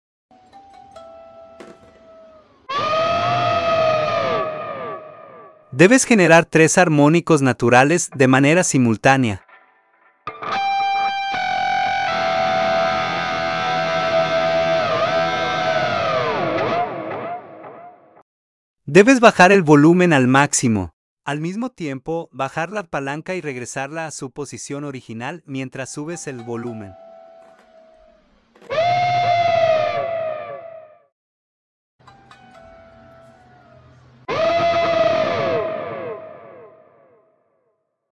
¿Cómo hacer el sonido de elefante que hace Van Halen? Requisitos: - Guitarra con floyd rose o trémolo.
- High Gain.